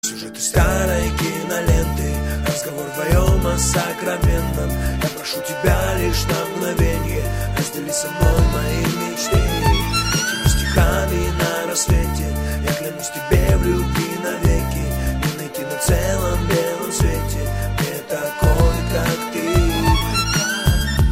Главная » Файлы » Hip-Hop, RnB, Rap